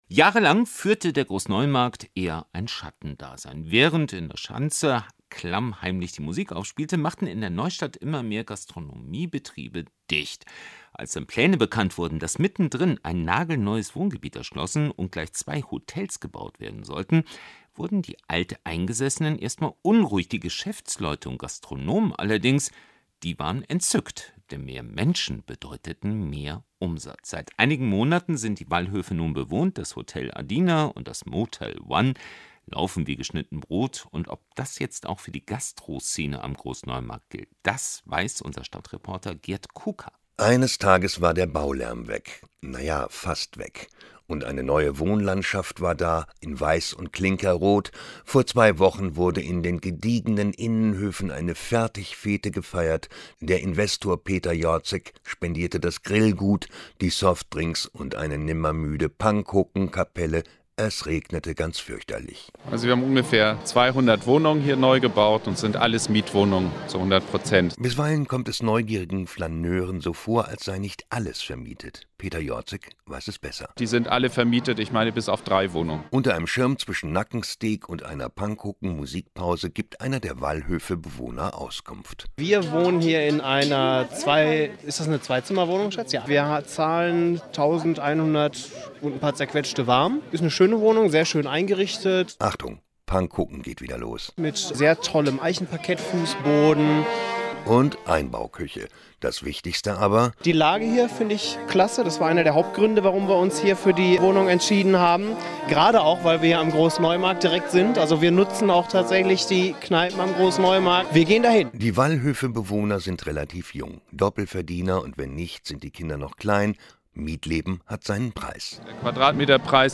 Juli 2011 / 10:15 Uhr) Radiosendung zum Stadtteil auf NDR 90,3.